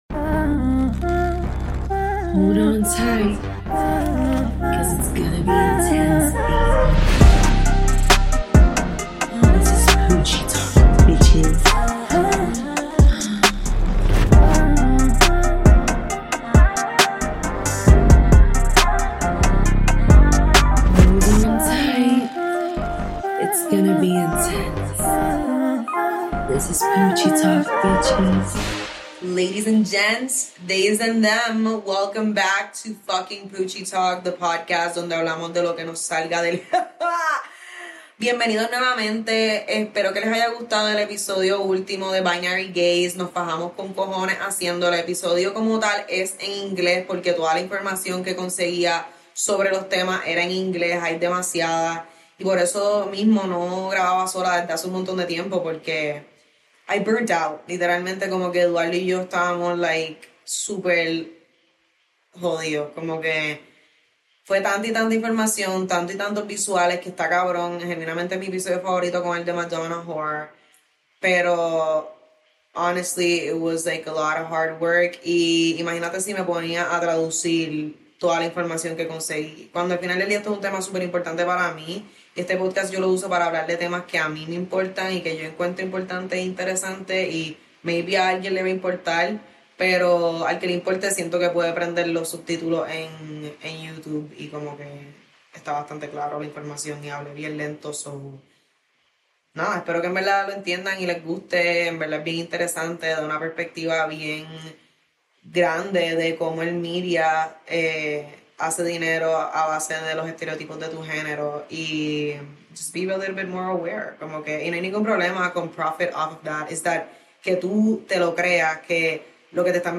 So this ep is basically a life update plus me being vulnerable exposing my 12 year old self. PS the audio sucks cuz I recorded everything on my camera :( sorry love u